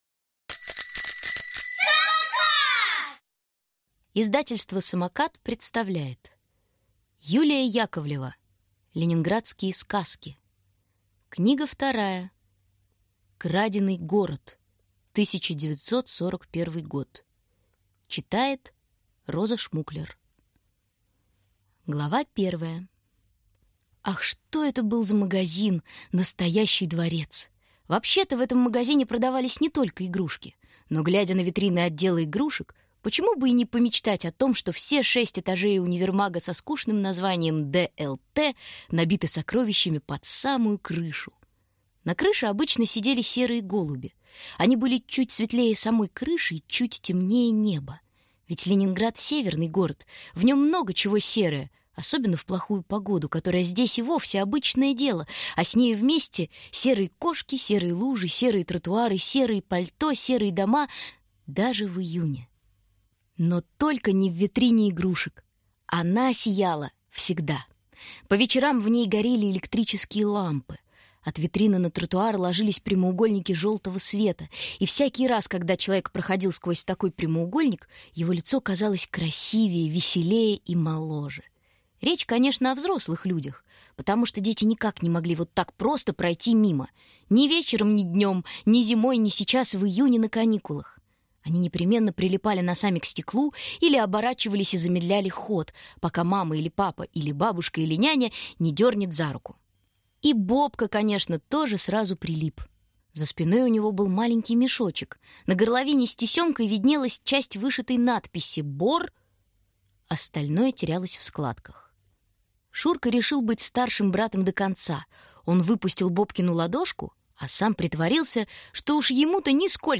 Аудиокнига Краденый город: 1941 год | Библиотека аудиокниг